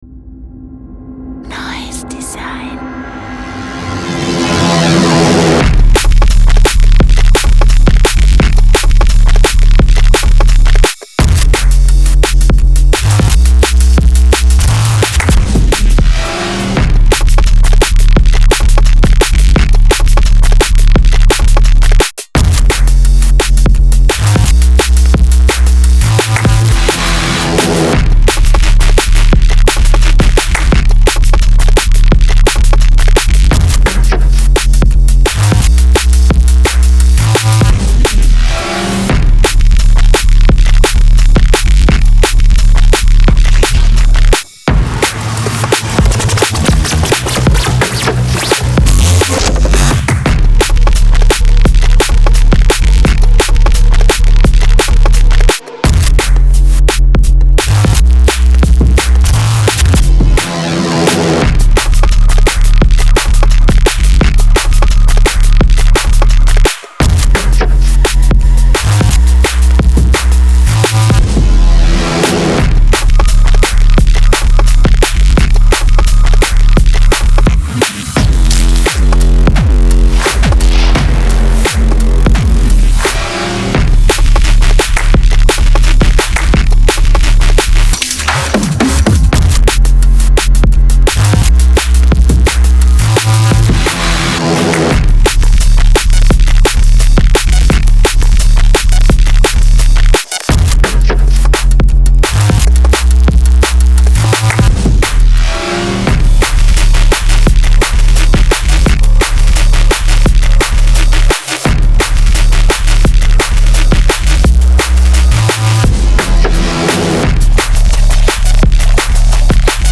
Genre:Drum and Bass
デモサウンドはコチラ↓
75 Bass Loops
43 Full Drum Breaks